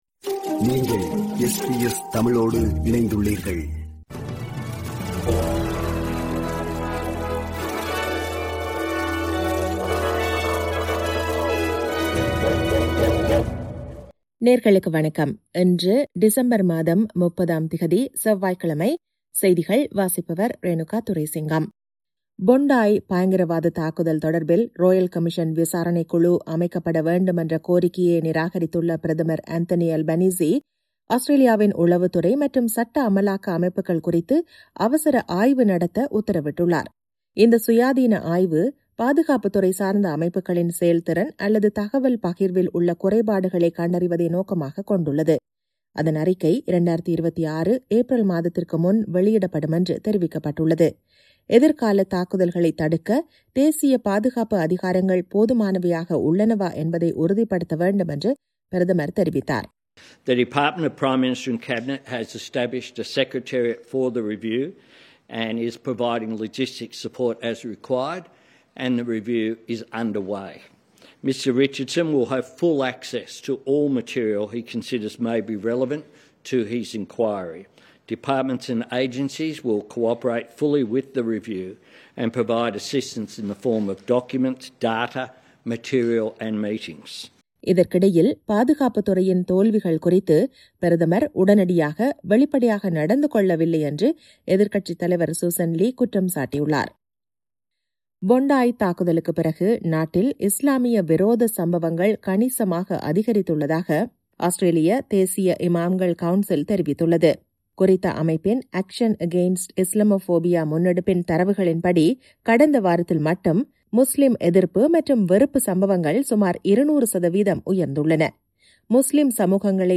இன்றைய செய்திகள்: 30 டிசம்பர் 2025 செவ்வாய்க்கிழமை